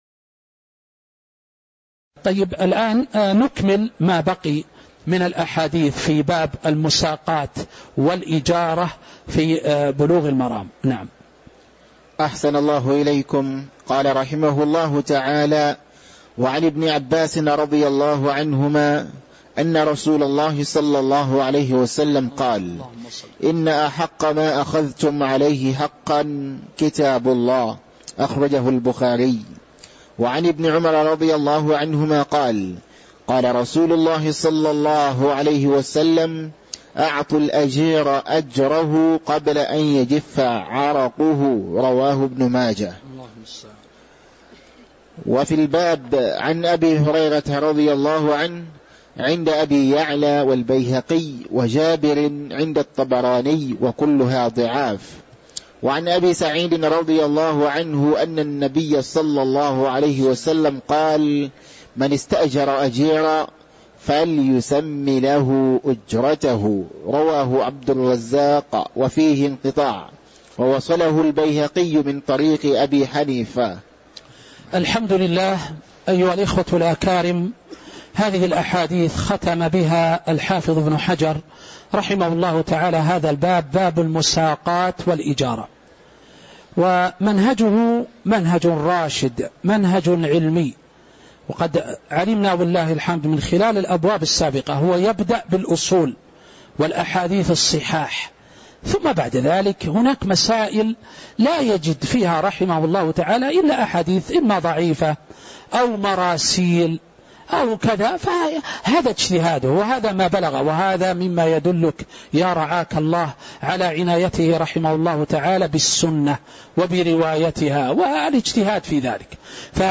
تاريخ النشر ١٥ جمادى الآخرة ١٤٤٦ هـ المكان: المسجد النبوي الشيخ